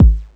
cch_kick_one_shot_low_punch_bit.wav